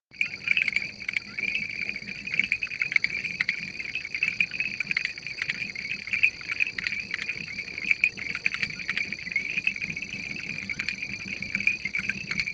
Ranita de Zarzal o trepadora
Suelen vocalizar todo el año llegando a generar coros muy conspicuos.
ranita-de-zarzal.mp3